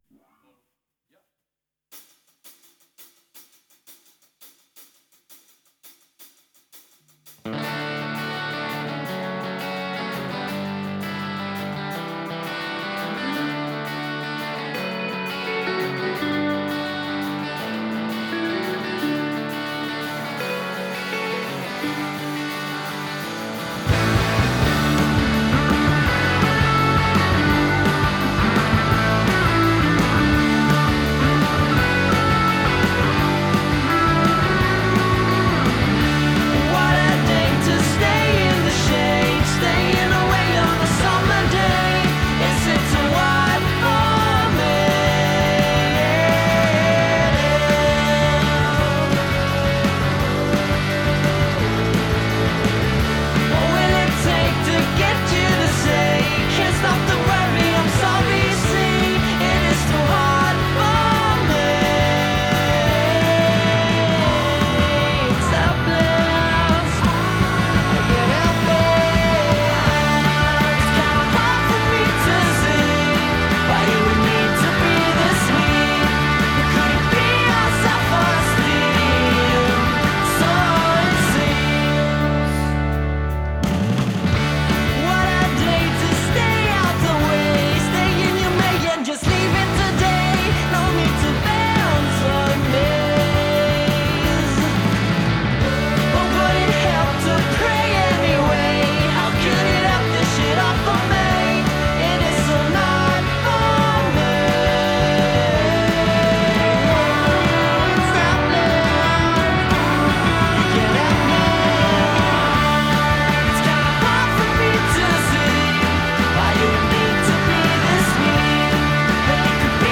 a relentlessly sunny, hooky sound
Blissful harmonies over ringing guitars